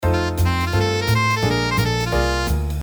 5連符打ち込み（クリックで音源再生します）